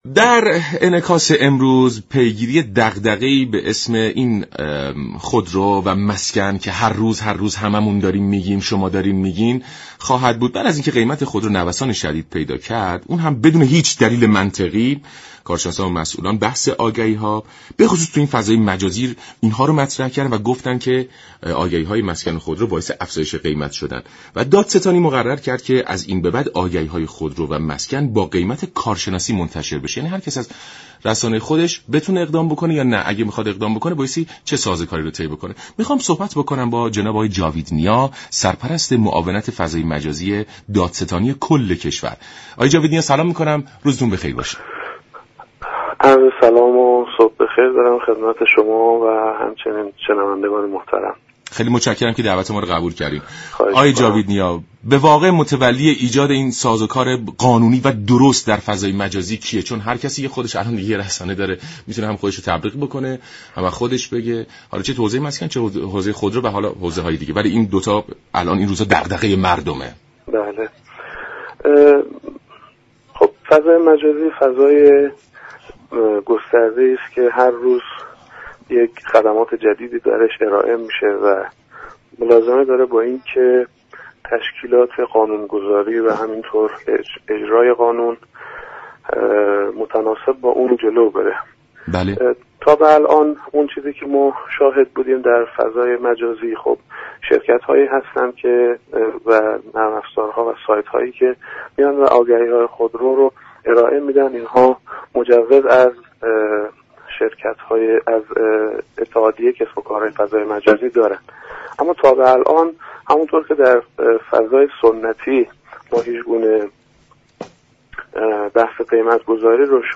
جاوید نیا سرپرست معاونت امور فضای مجازی دادستان كل كشور در گفت و گو با برنامه سلام صبح بخیر رادیو ایران به دستور دادستانی برای انتشار آگهی های تبلیغاتی خودرو با قیمت كارشناسی شده اشاره كرد و گفت: از آنجا كه فضای مجازی برای سودجویان امكان گسترده ای را فراهم كرده تا از طریق شبكه های اجتماعی دست به اقدامات منفعت طلبانه بزنند دستگاه متولی برای مقابله با این پدیده اقداماتی را در دستور كار خود قرار داده است.